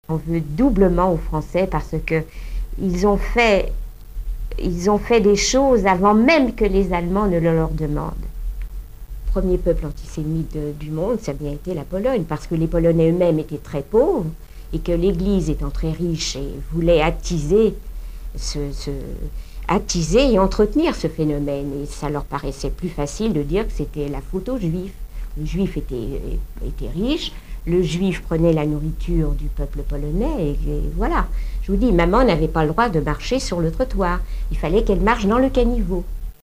Témoignages de survivants.